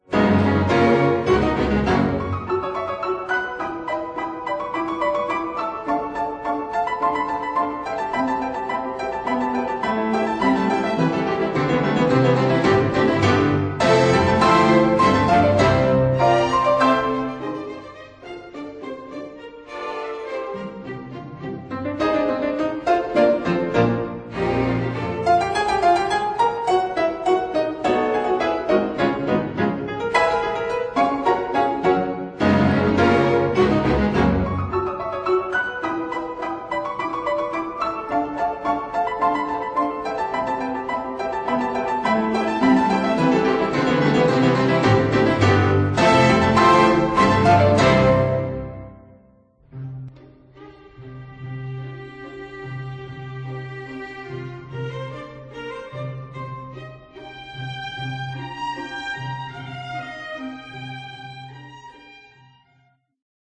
ไวโอลิน 2
วิโอลา
เชลโล
ดับเบิลเบส